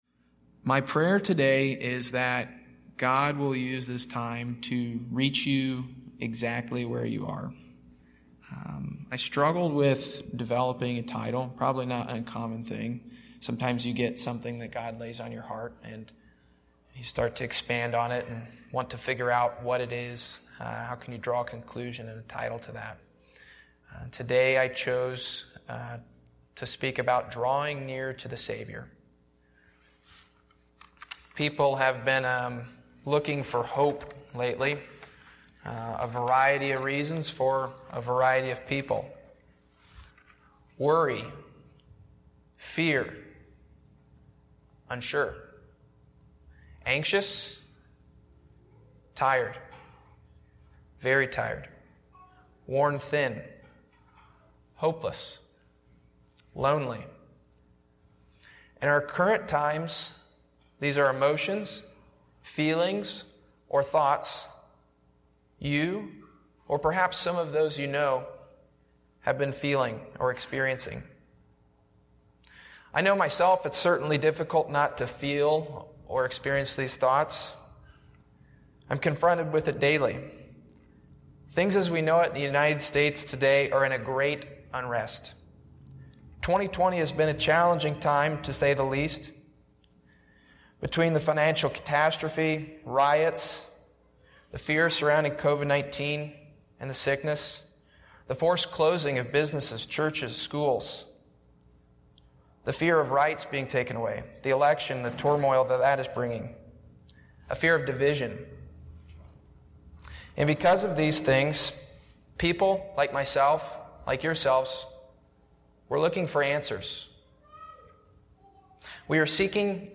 Speaker